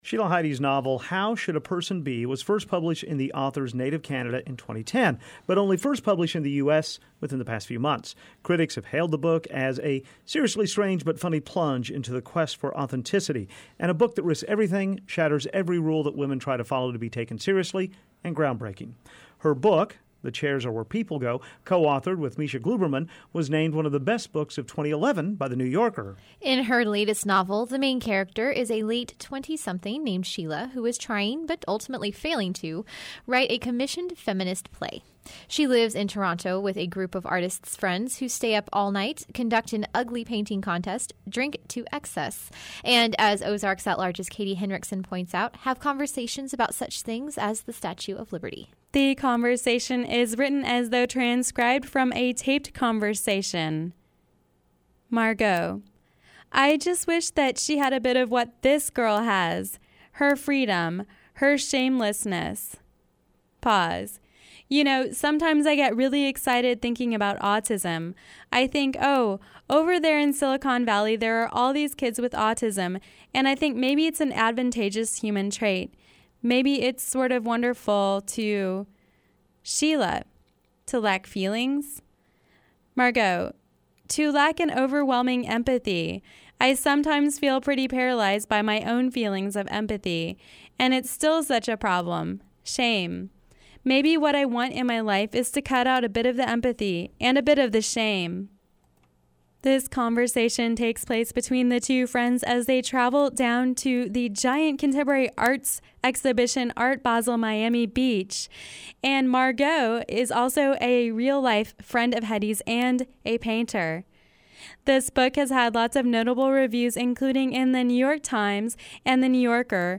Book Review: How Should a Person Be: A Novel from Life